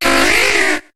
Cri de Kokiyas dans Pokémon HOME.